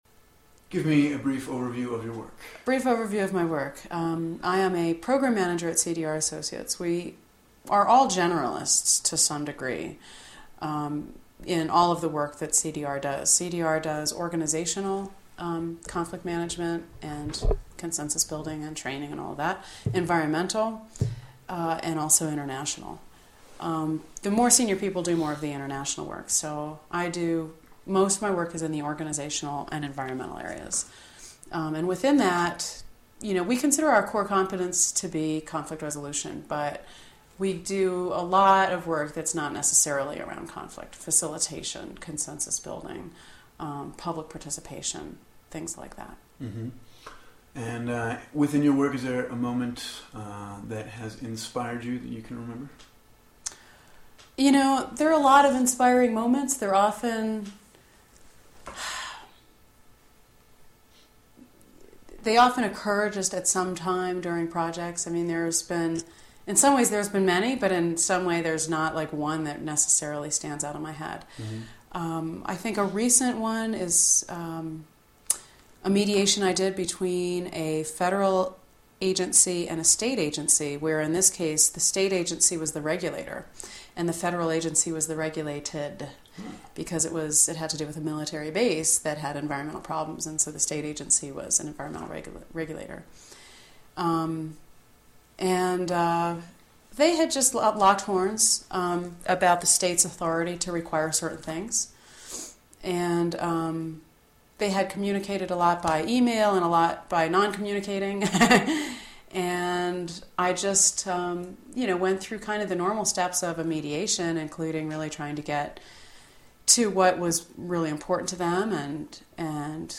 Listen/Read Selected Interview Segments on the Following Topics